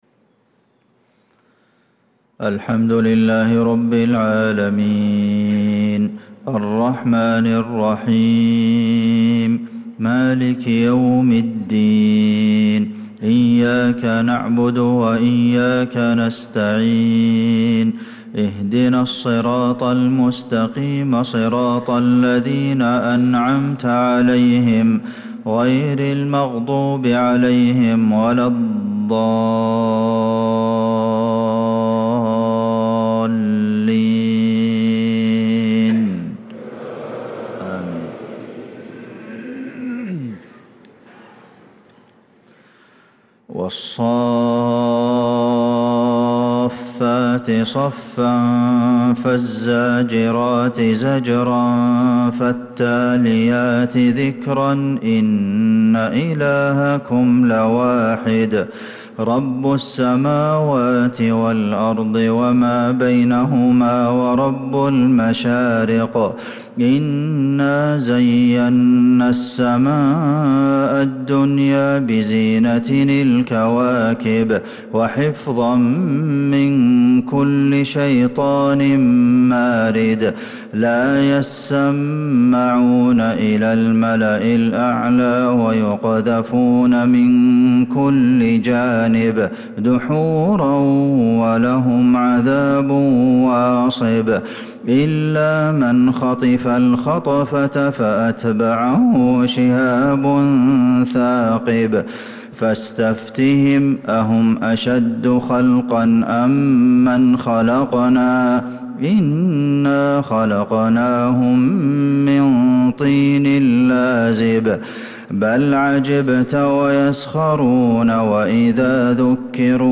صلاة الفجر للقارئ عبدالمحسن القاسم 1 محرم 1446 هـ